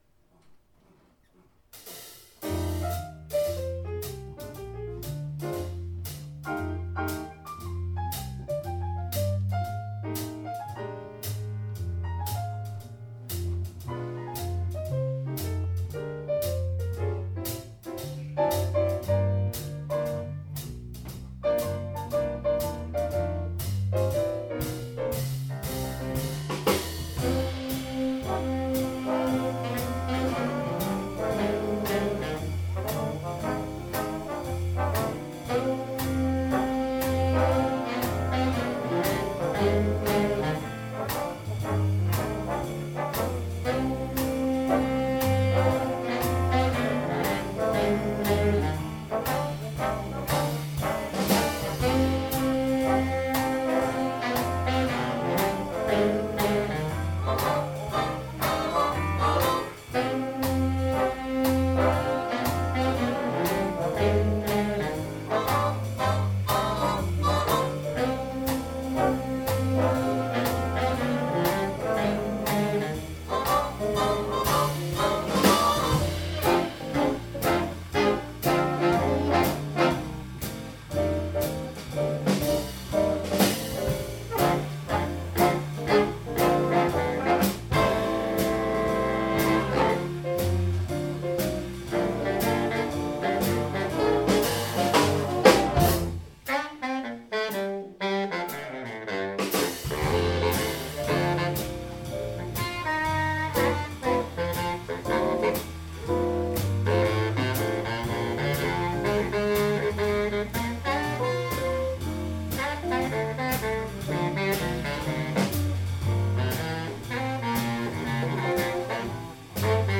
- Hayburner Big Band 26. oktober 2014
Introduktion af nummeret